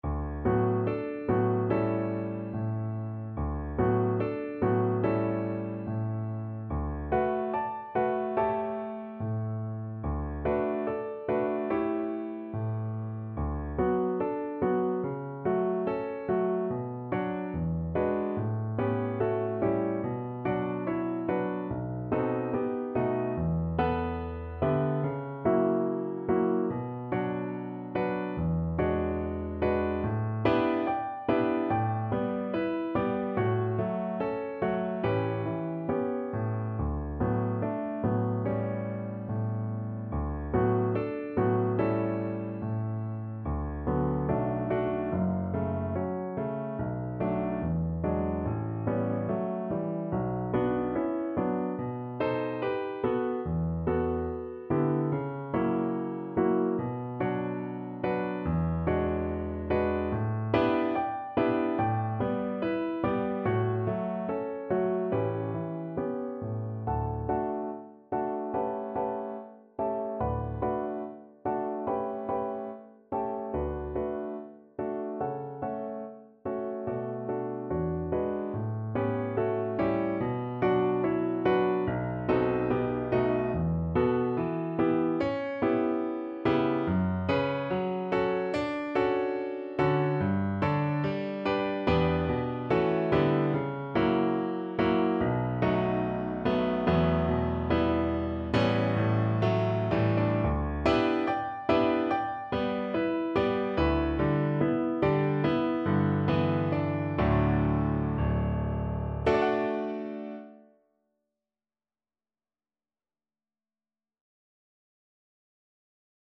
4/4 (View more 4/4 Music)
~ = 72 In moderate time
Classical (View more Classical Cello Music)